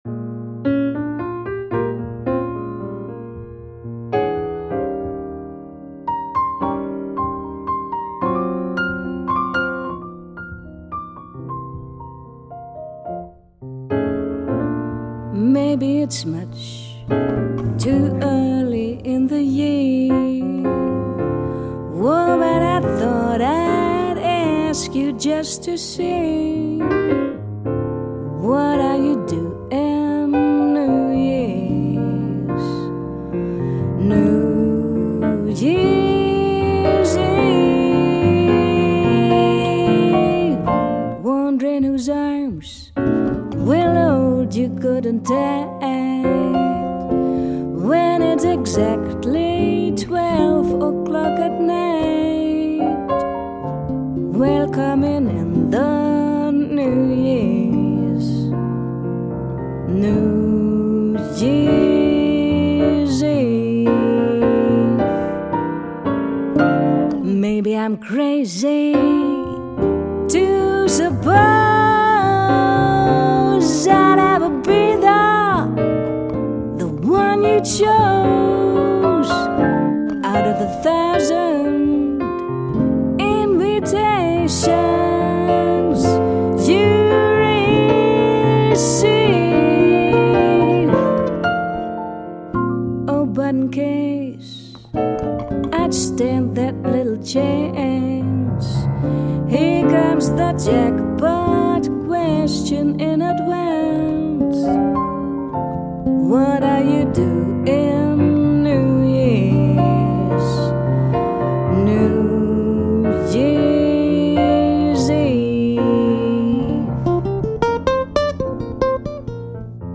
piano
guitar
ac. bass
drums